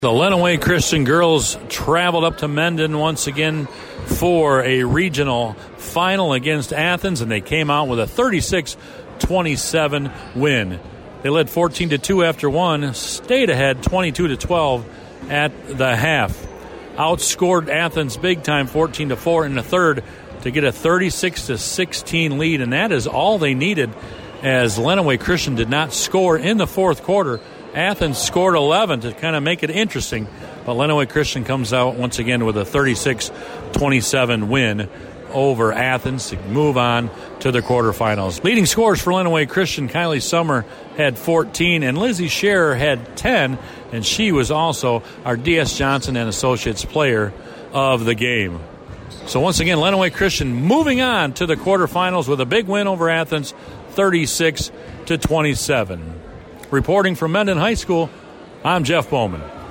Mendon, MI – The Lenawee Christian Lady Cougars traveled out to Mendon High School for their Regional Championship game Thursday night, and came away with a defensive victory.